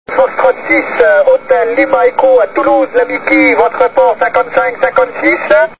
Les fichiers à télécharger sont compressés au format MP3 à 1ko/sec, ce qui explique la très médiocre qualité du son.
bande 40 m dimanche 25/11/2001, émission en BLI (bande latérale inférieure). Peu de QRM.